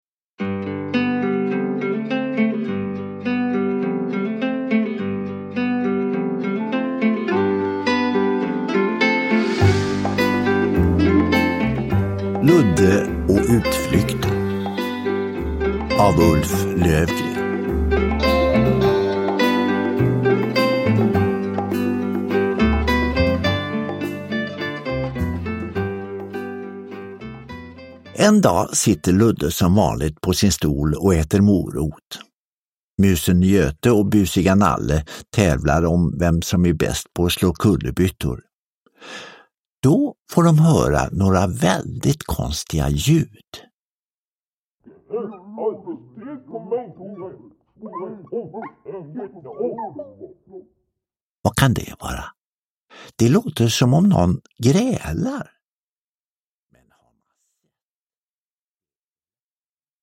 Ludde och utflykten – Ljudbok – Laddas ner